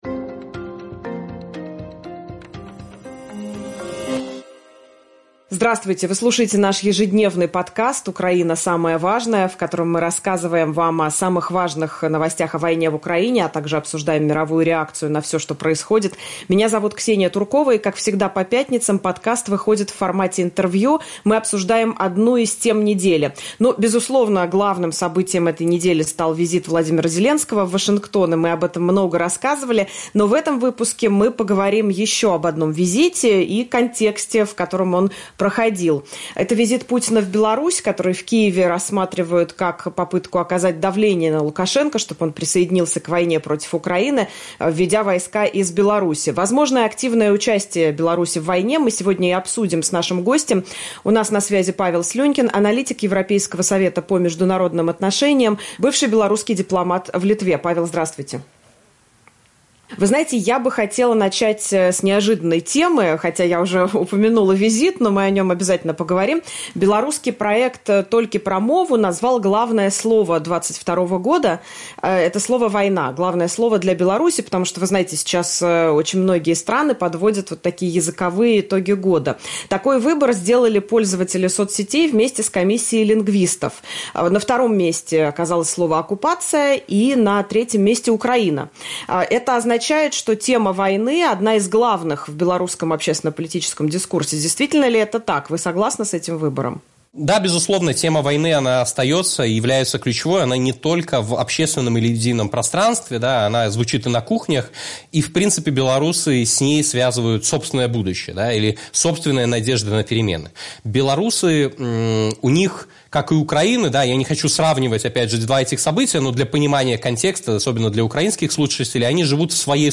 Украина. Самое важное. Роль Беларуси в войне (интервью)